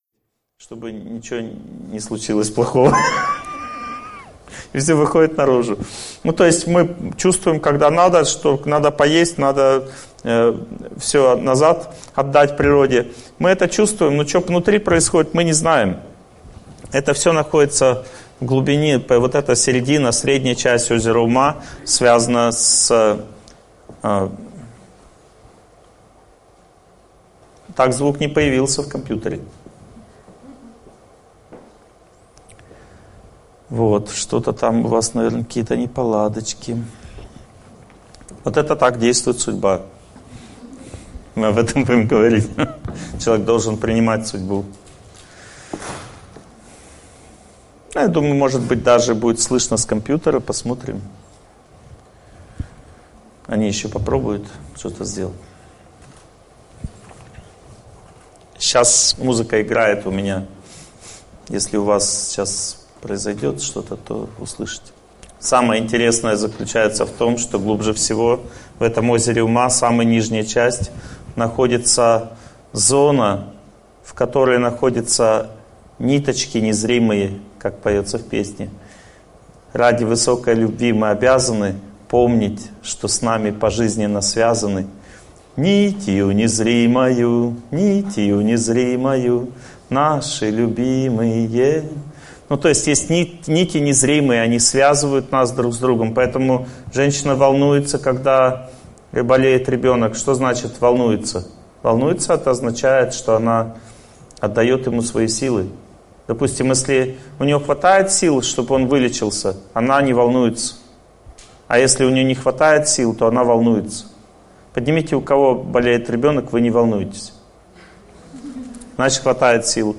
Аудиокнига Победа над стрессом и трудностями судьбы. Глава 1 | Библиотека аудиокниг